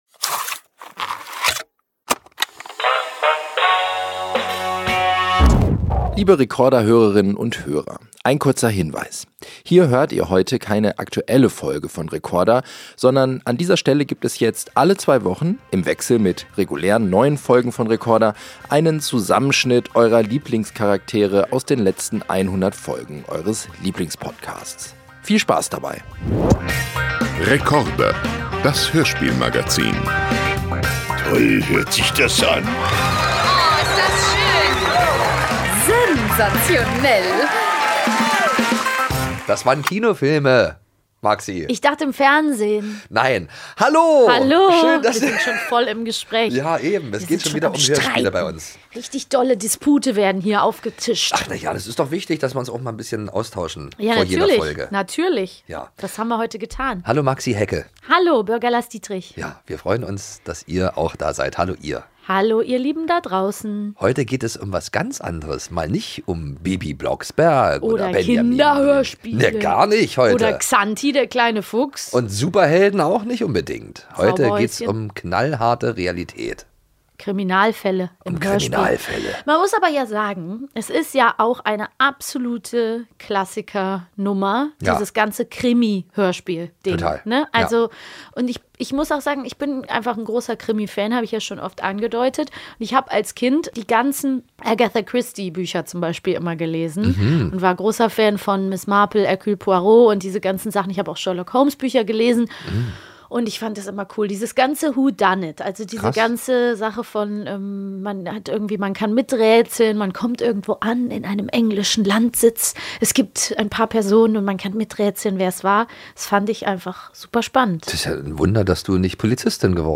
Beschreibung vor 6 Monaten Jeder hat sie, ob winzig klein oder riesengroß: Das Thema dieser Sonderfolge sind Geheimnisse. Mit einem “Pater Brown” Hörspiel, klassischem Krimi-Intro, sympathischem Erzähler und ganz viel Raum zum Miträtseln startet diese Folge.